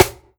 SNARE_STAB_N_TWIST.wav